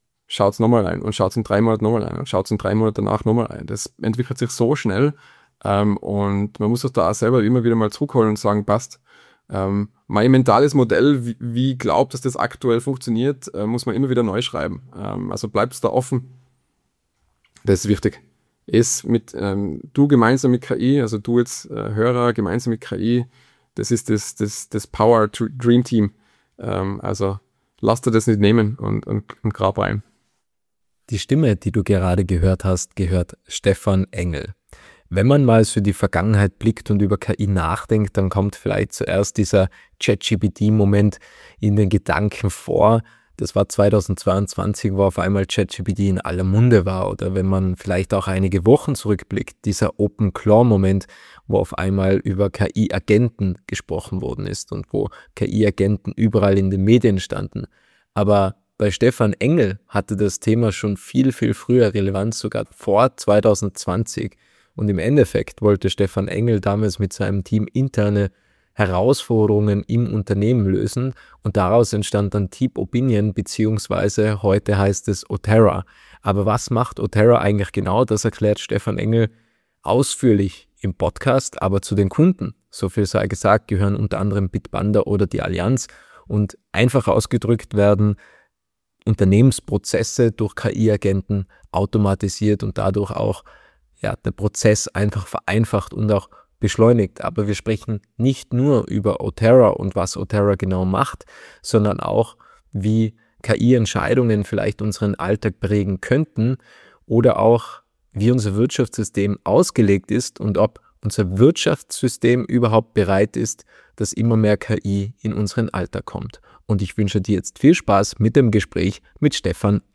Im Podcast-Interview